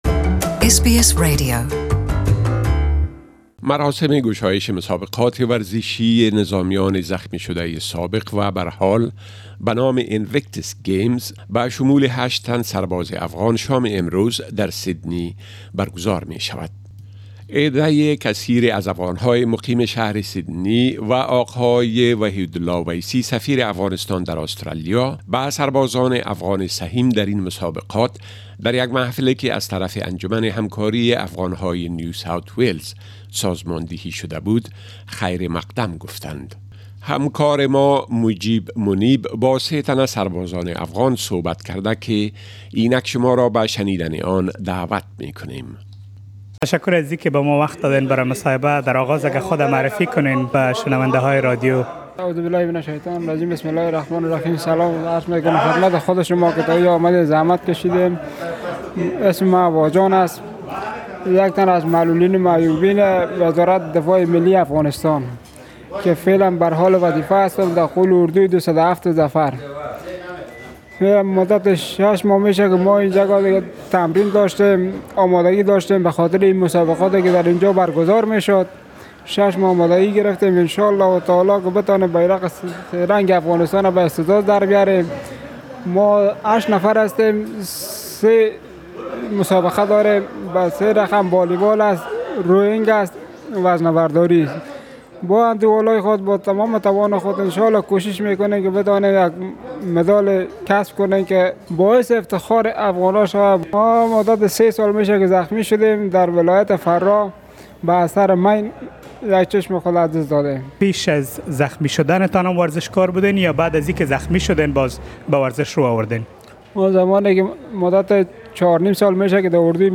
A number of Afghan military personnel are participating in the Inictus games to be launched this evening. We interviewed some of them in Dari language and can be heard here.